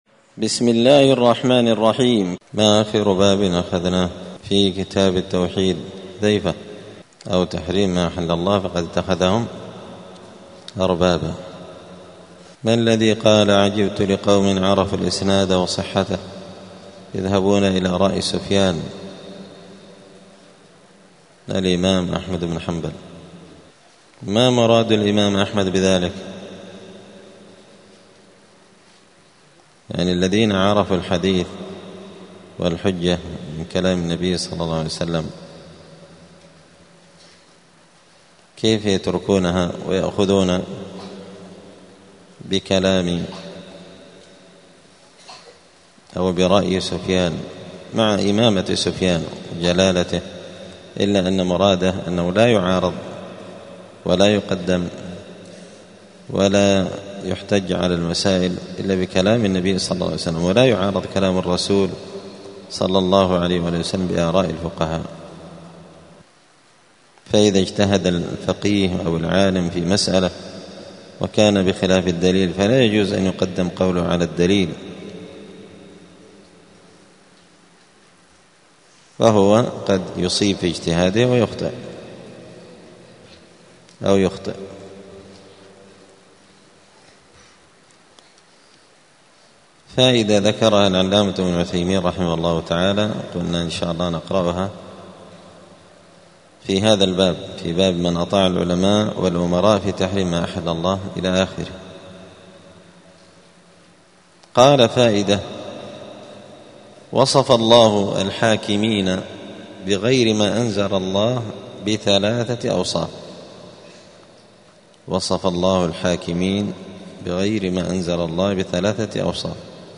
دار الحديث السلفية بمسجد الفرقان قشن المهرة اليمن
الأربعاء 25 جمادى الأولى 1446 هــــ | الدروس، حاشية كتاب التوحيد لابن قاسم الحنبلي، دروس التوحيد و العقيدة | شارك بتعليقك | 20 المشاهدات